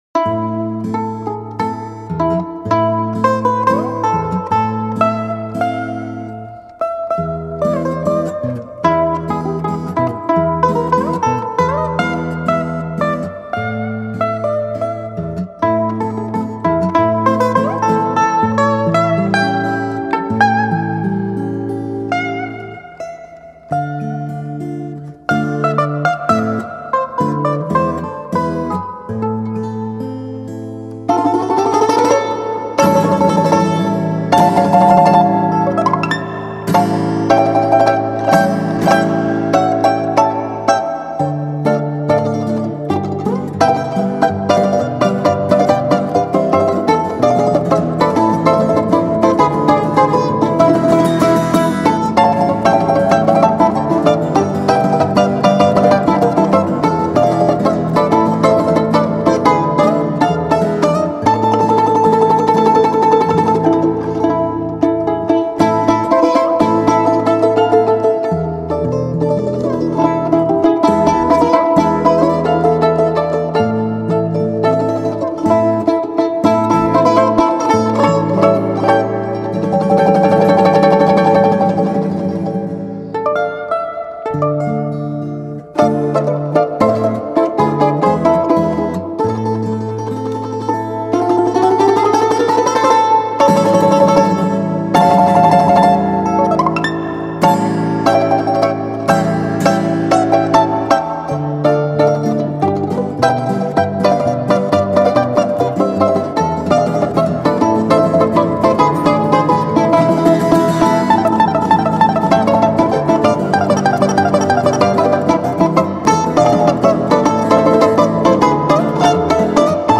Балалайка